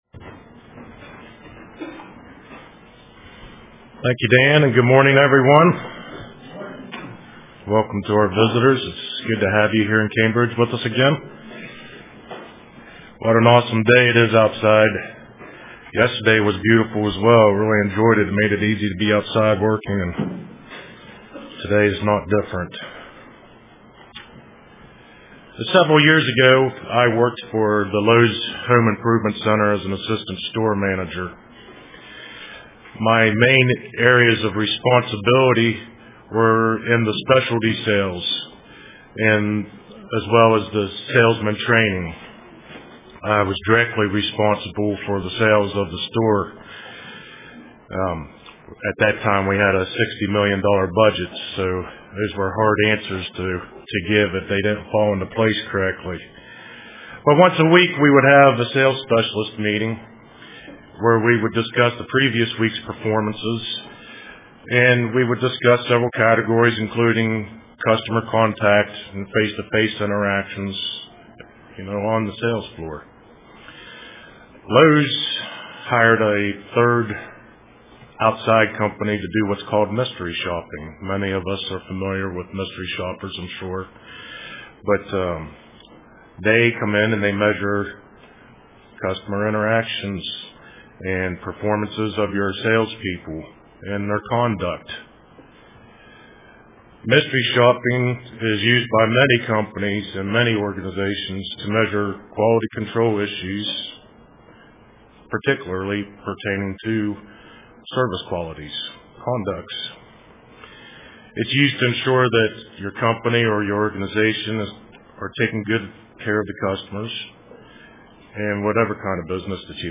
Print Stepping up to a Higher Standard UCG Sermon Studying the bible?